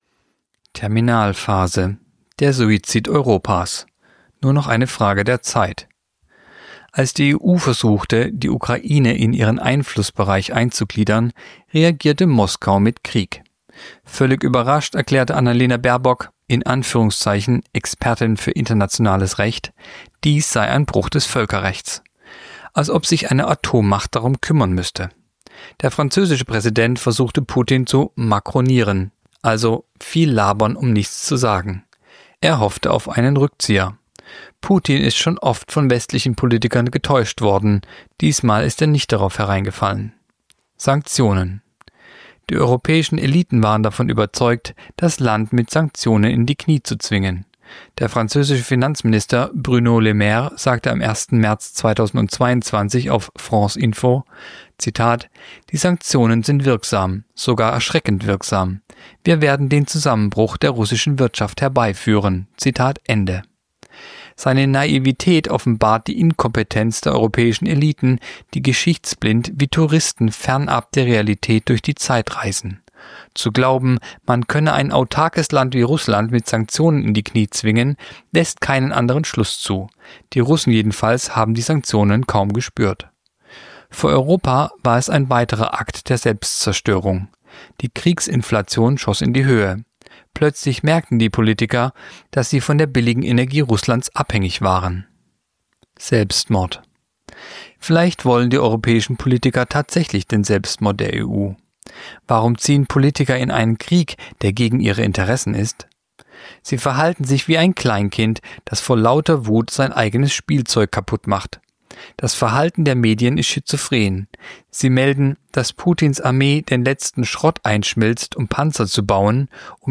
Kolumne der Woche (Radio)Der Suizid Europas
(Sprecher)